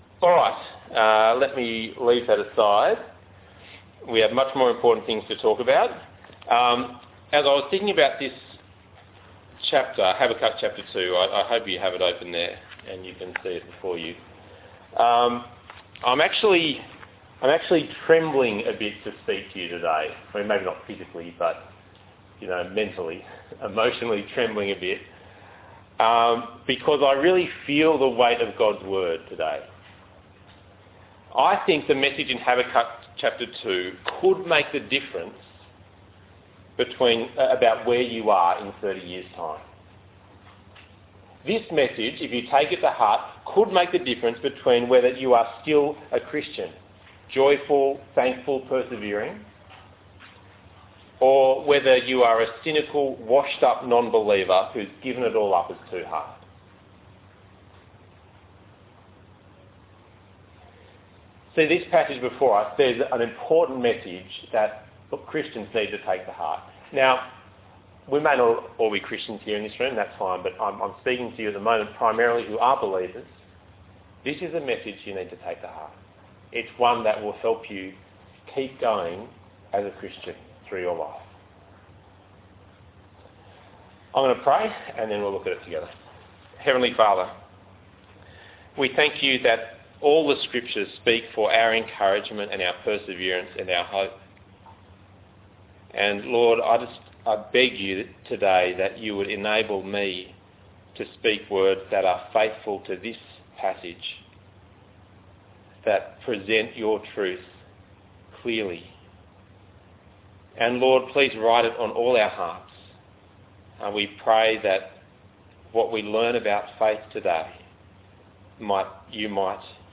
Habakkuk 2:1-20 Talk Type: Bible Talk « God